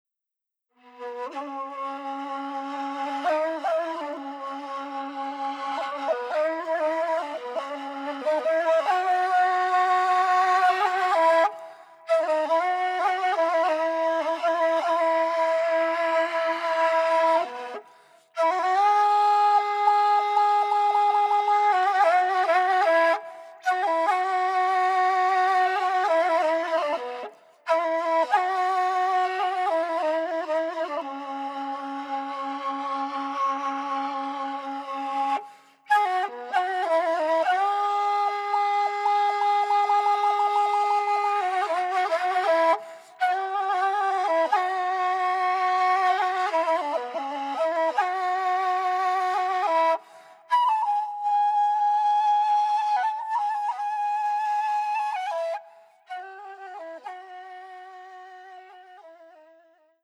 traditional Bulgarian kaval music